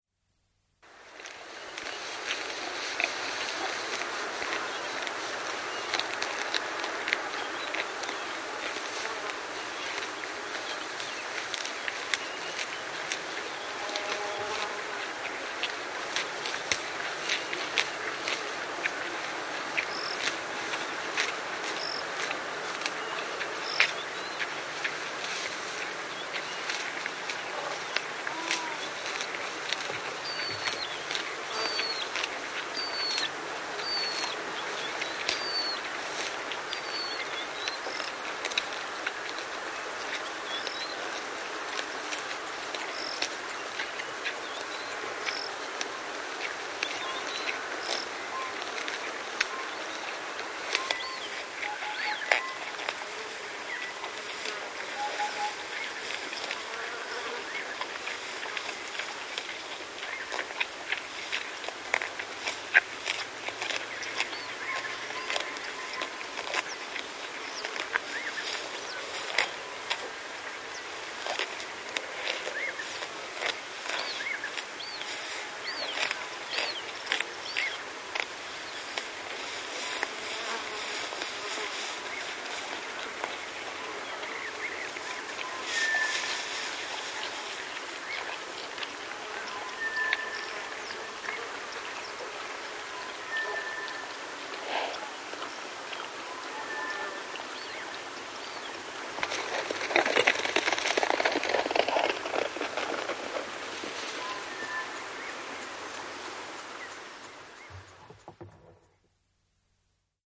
Звук бородавочника, поедающего добычу в густой траве Маасай Мара — заповедник на юго-западе Кении